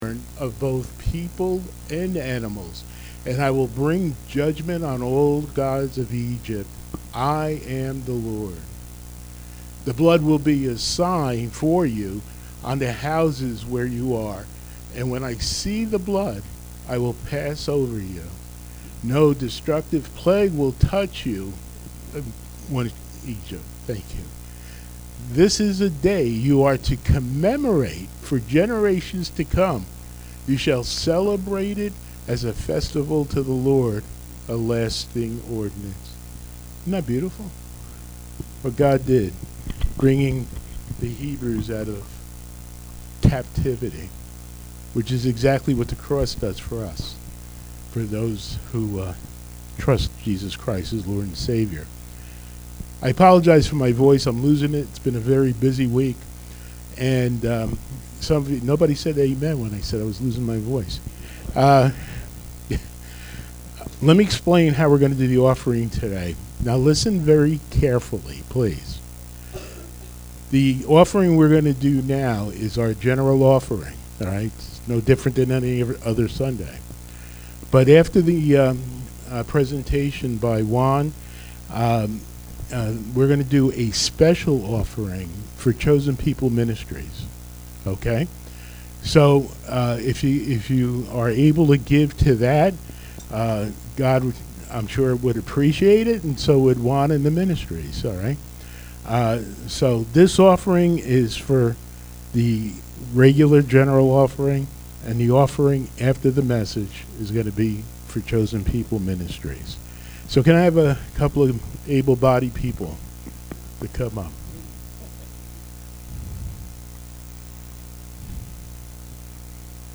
Series: Special Worship Service